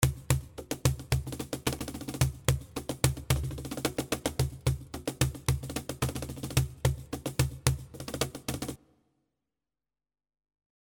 110 Bpm Cajon (7 variations)
7 cajon loops in 110 bpm in baladi style.
The cajon loops are in 110 bpm playing baladi style.
The 7 loops you can download are with reverb, 1.5 seconds long, but by buying this product